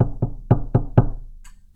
doortap.wav